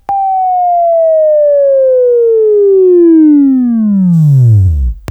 Sweeping Training
Buzz